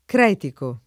cretico [ kr $ tiko ]